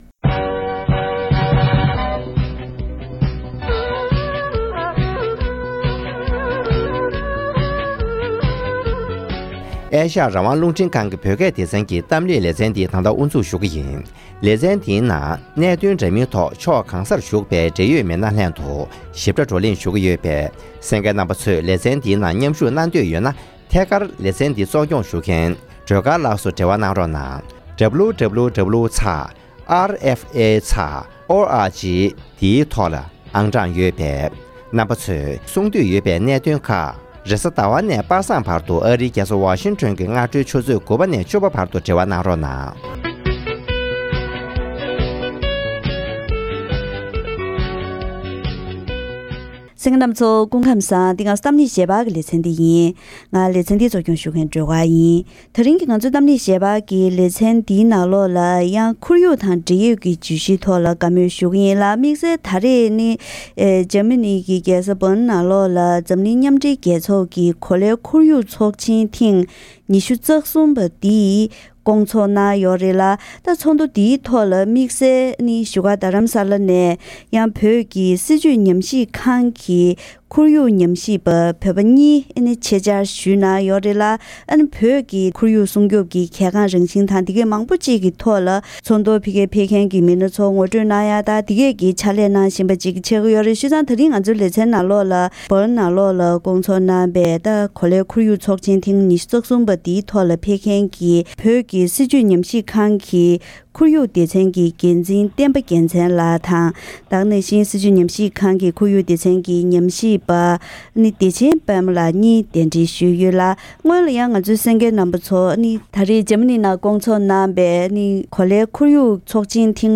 གཏམ་གླེང་ཞལ་པར་ལེ་ཚན